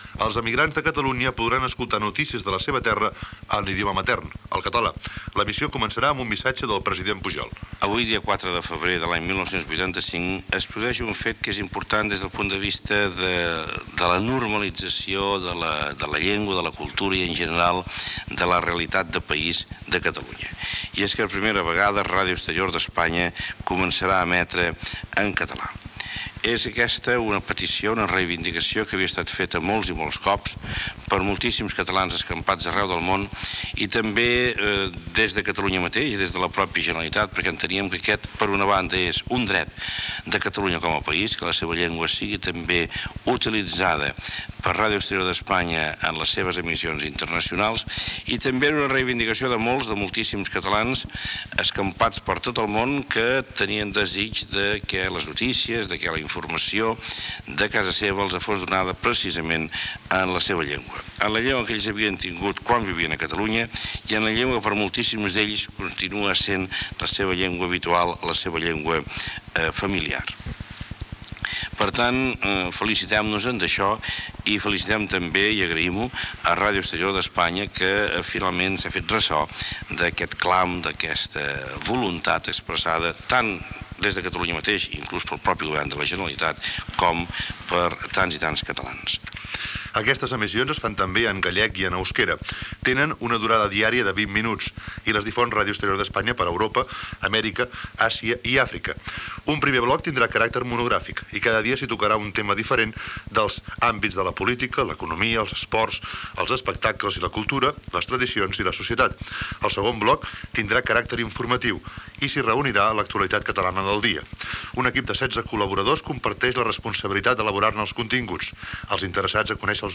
861add73798e6195a60631d98405d7d9e12c06d3.mp3 Títol Ràdio 4 - Radiodiari Emissora Ràdio 4 Cadena RNE Titularitat Pública estatal Nom programa Radiodiari Descripció Inici de les emissions en català de Radio Exterior de España. Gènere radiofònic Informatiu